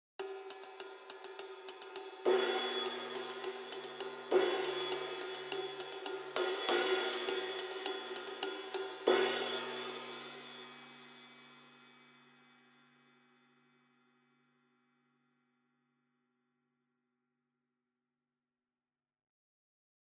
Тарелки серии Custom обладают широким частотным диапазоном, теплым плотным звуком и выдающейся музыкальностью.
Masterwork 20 Custom Flat Ride sample
Custom-Ride-20-Flat.mp3